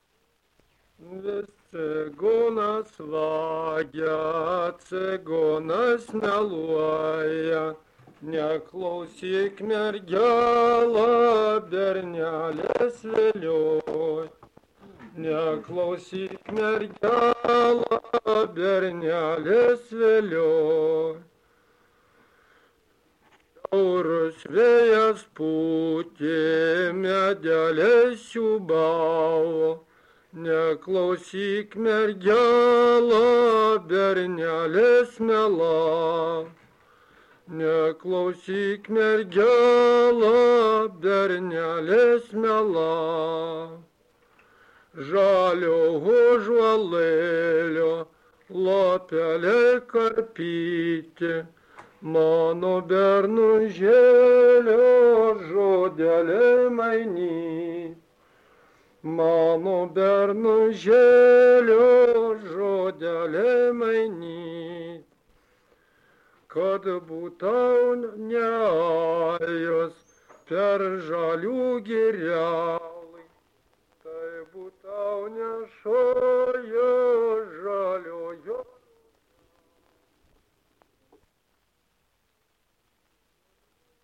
Dalykas, tema daina
Erdvinė aprėptis Barčiai (Varėna)
Atlikimo pubūdis vokalinis